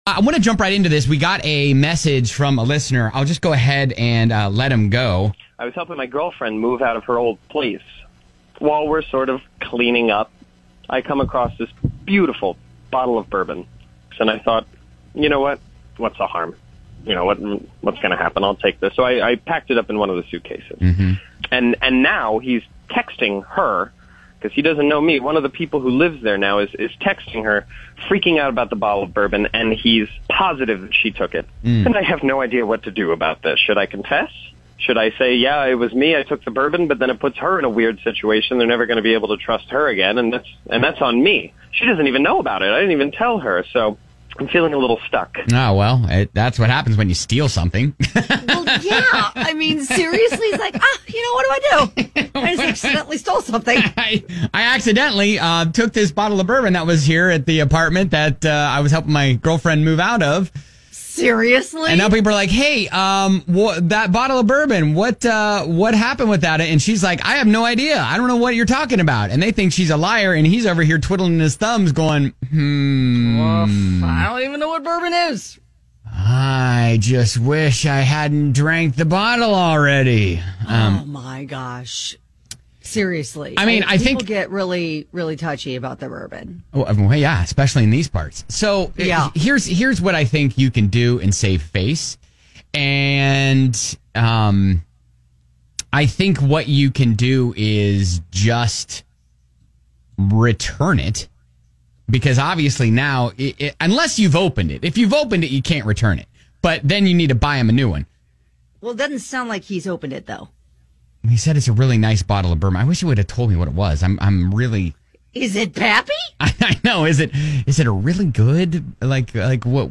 We got a call from a listener who was wondering what to do about a bottle of bourbon that wound up in his hands after he helped his girlfriend move. Her old roommates are accusing her and she knows nothing about it!